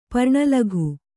♪ parṇa laghu